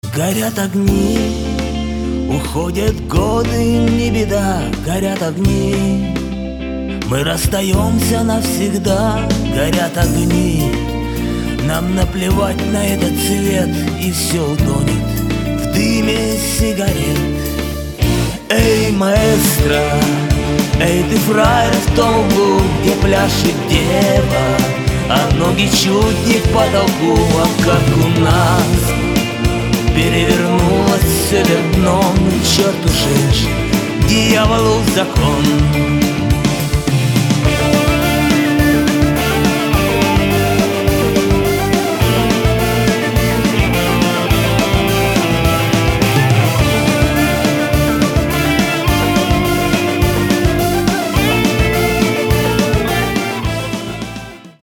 • Качество: 256, Stereo
мужской вокал
грустные
блатные
тюремные